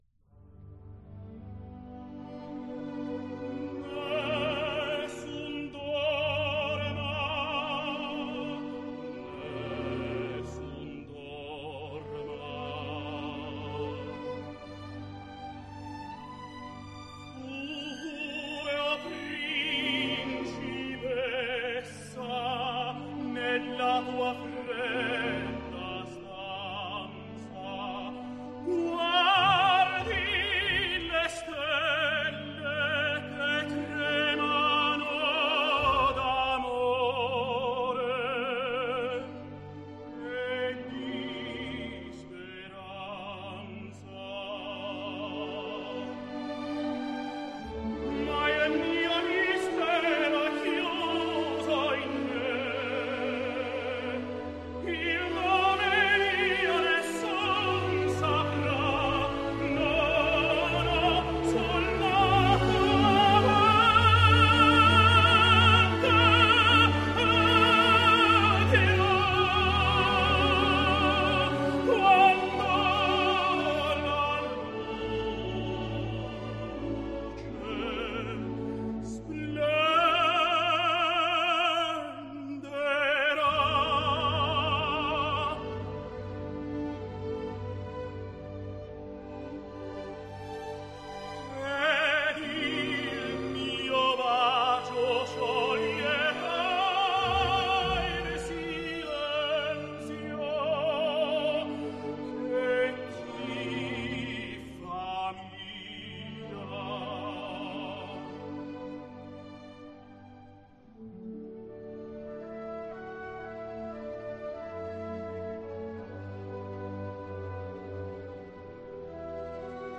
重新以“Spatializer”的3D编码方式处理
CD提示为：本专辑低音频率最低可达到5HZ，播放时请适当控制音量的输出，否则可能对你的设备产生损害。
(电影音乐)